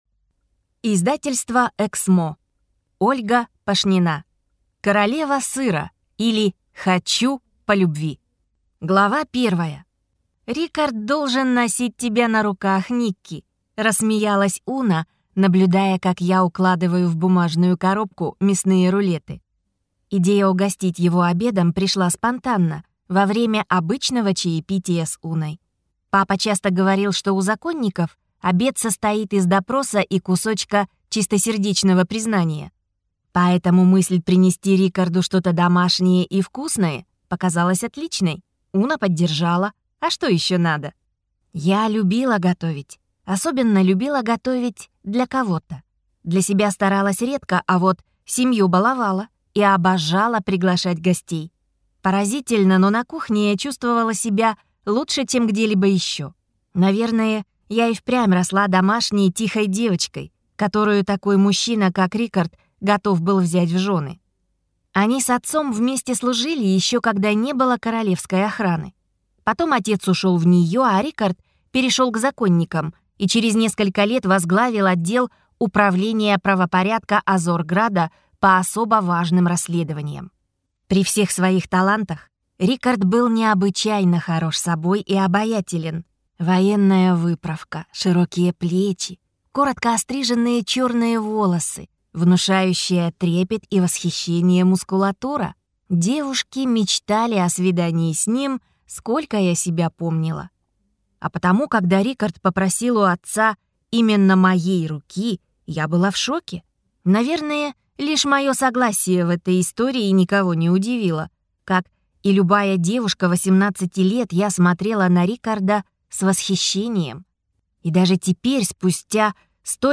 Аудиокнига Королева сыра, или Хочу по любви!